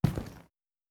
Plastic footsteps
plastic3.wav